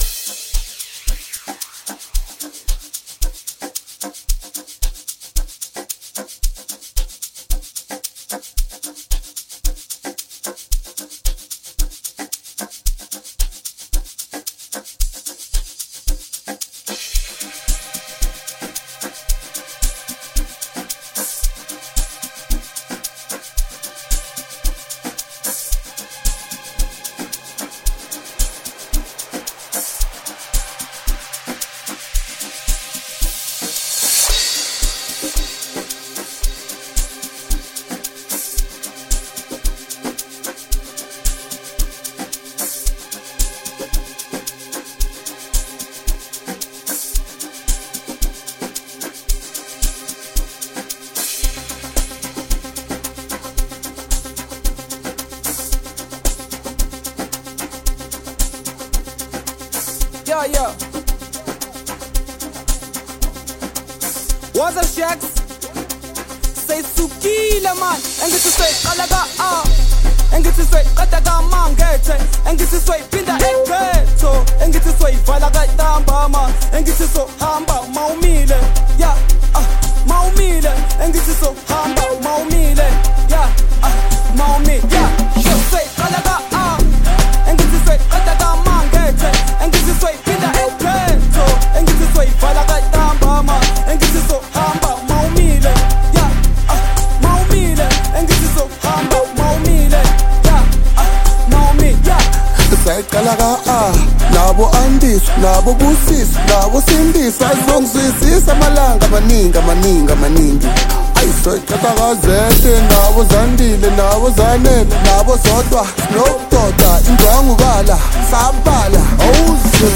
• Genre: Electronic